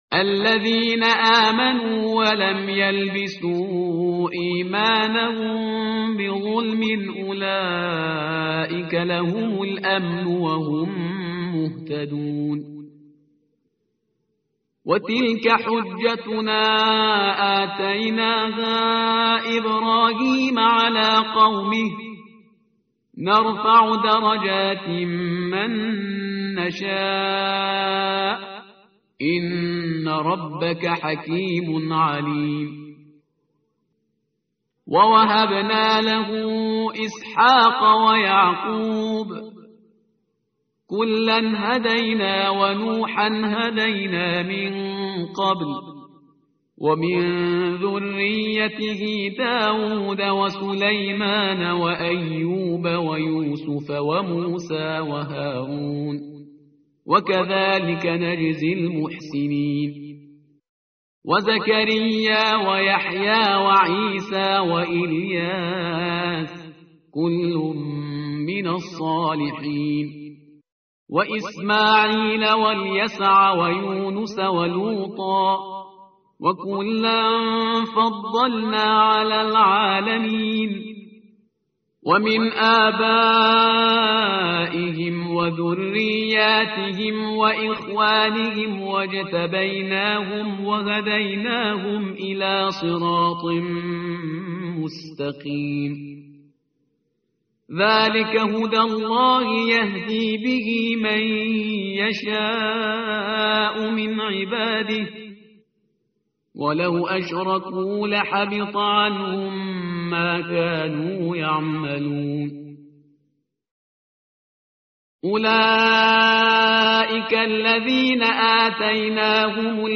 tartil_parhizgar_page_138.mp3